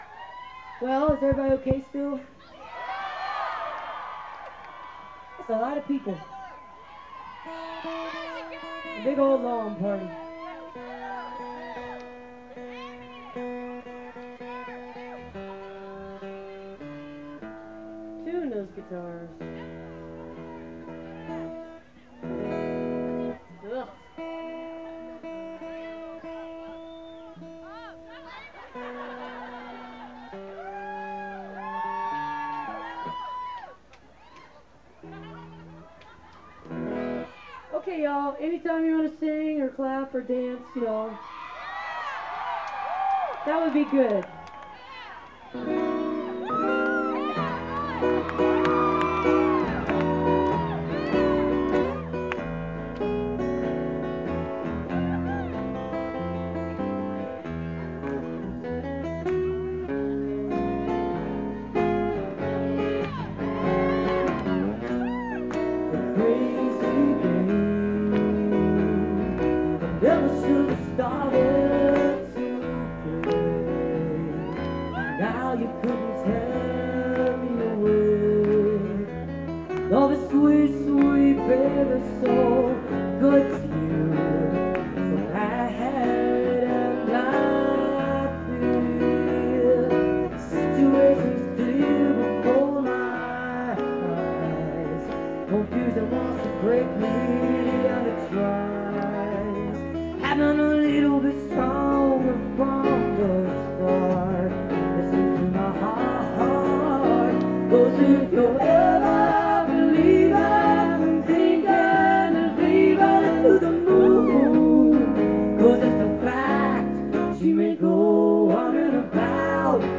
acoustic duo